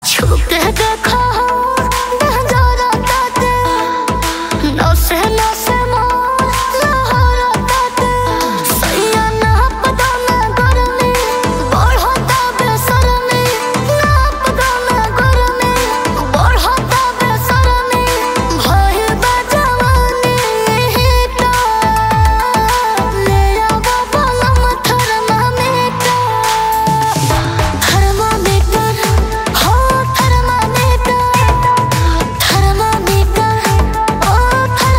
Playful, passionate vibes for calls or alerts.